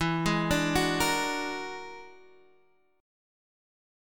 E9b5 Chord
Listen to E9b5 strummed